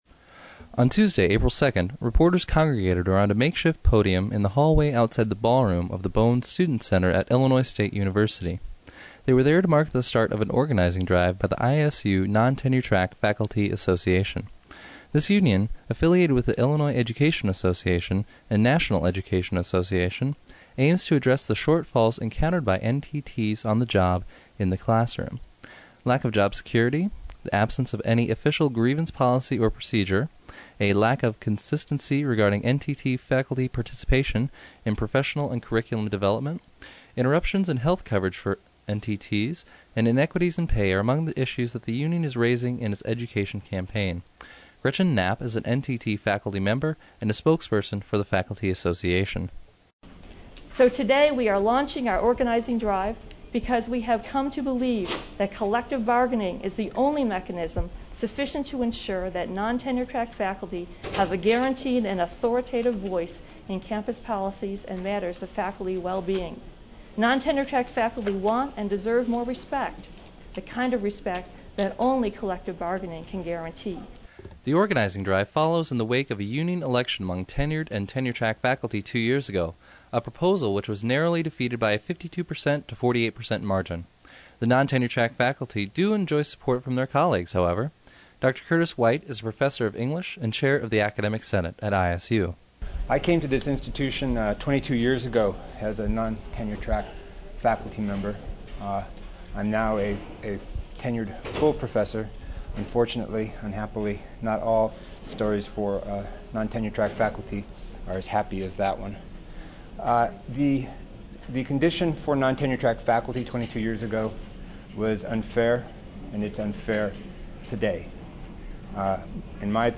Miscellaneous isu_ntt_organizing_drive.mp3 (5927 k) Report produced for the 13 April 2002 edition of the "Illinois Labor Hour" on WEFT, Champaign's community radio station, about an organizing drive at Illinois State University aimed at non-tenure track faculty members. 14:26.